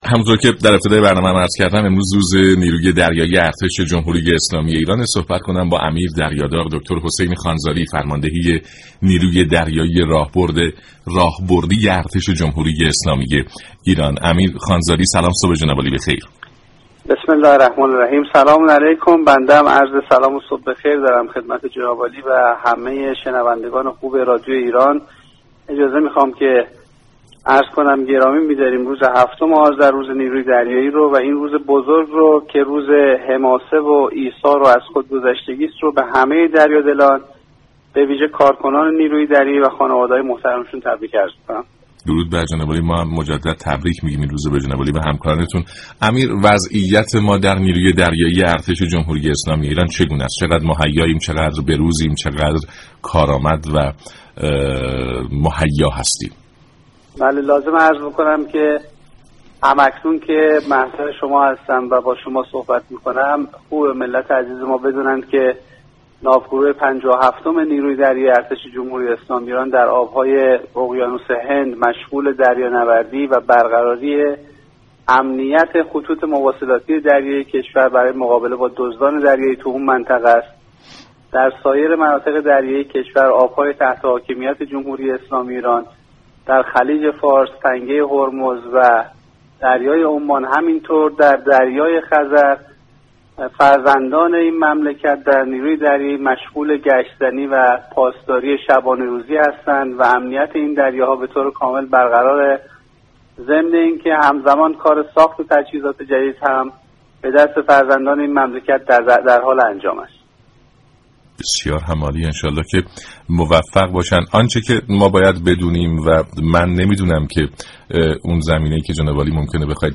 امیردریادار حسین خانزادی فرمانده نیروی دریایی ارتش جمهوری اسلامی ایران در سلام صبح بخیر